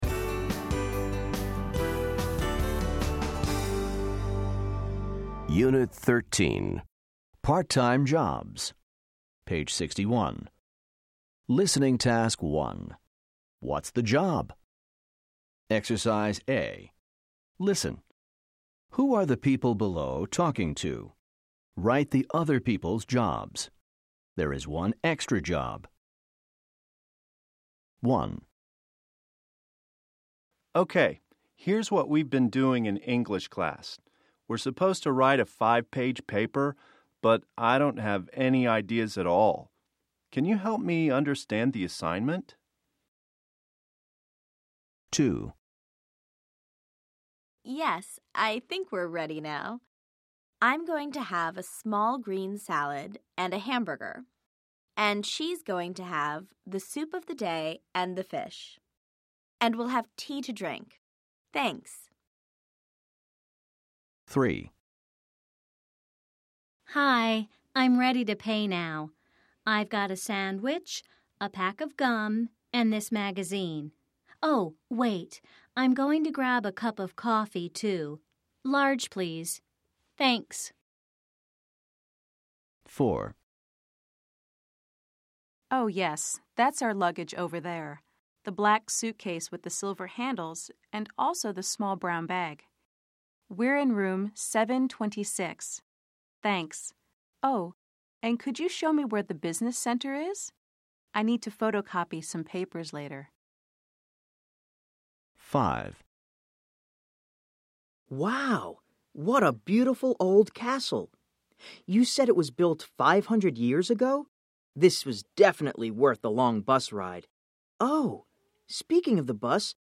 American English
Class Audio CDs include natural conversational recordings for the listening tasks in each unit, pronunciation practice, and expansion units containing authentic student interviews.